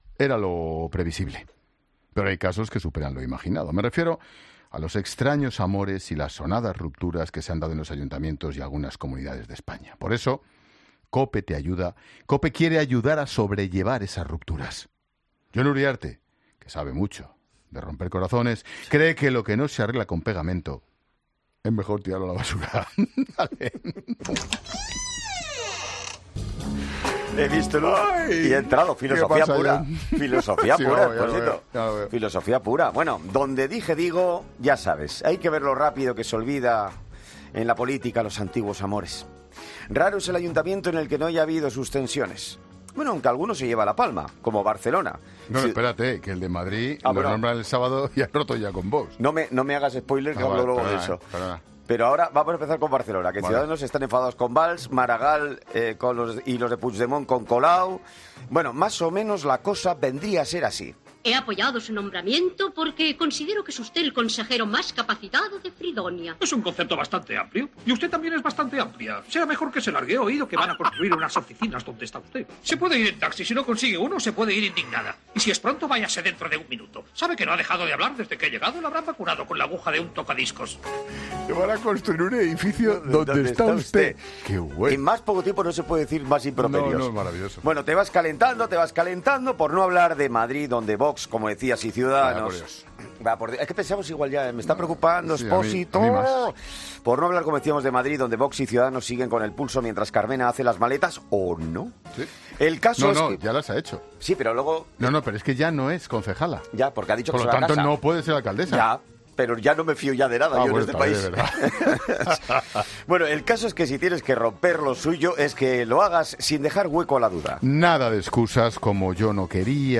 AUDIO: Jon Uriarte analiza con Ángel Expósito una de las noticias más importantes y destacadas del día, pero a través de su particular visión de las cosas